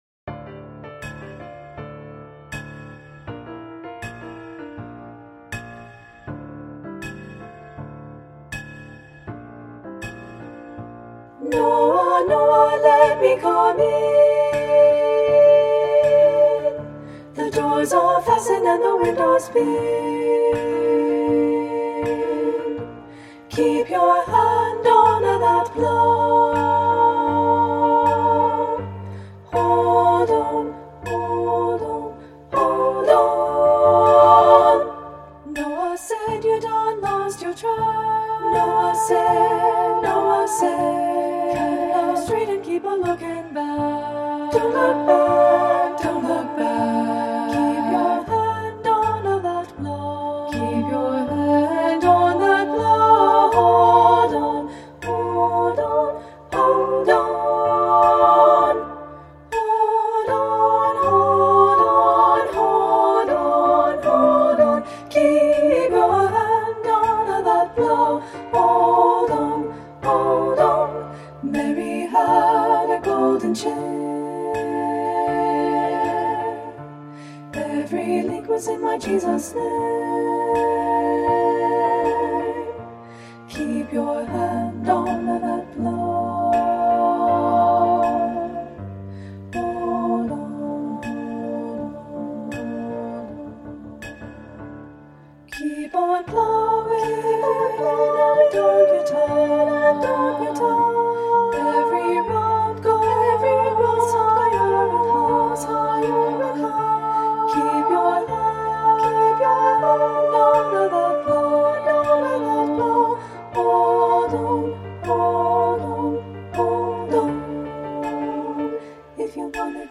SSA Voices with Piano and Optional Percussion
Traditional African-American Spiritual
• Soprano 1
• Soprano 2
• Alto
• Piano
• Percussion (opt.)
Studio Recording
Ensemble: Treble Chorus
Key: F major
Tempo: h = 80
Accompanied: Accompanied Chorus